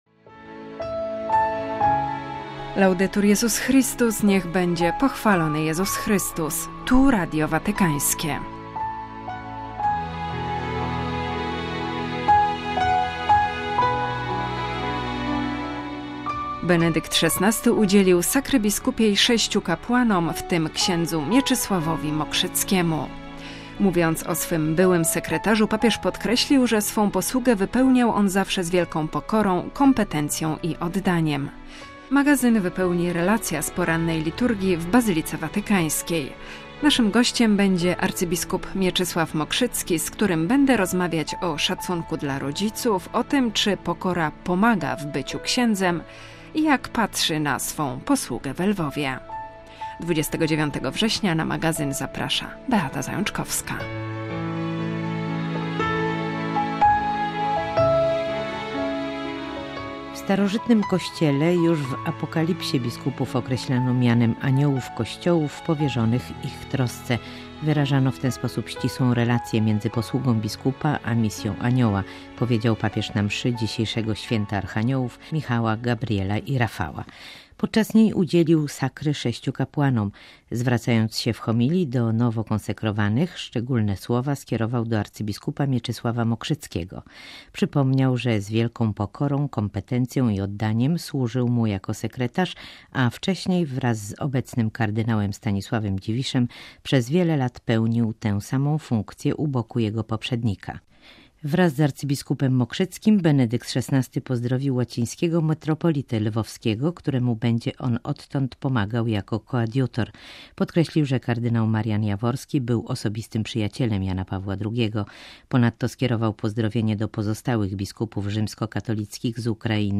Magazyn wypełnia relacja z porannej liturgii w bazylice watykańskiej.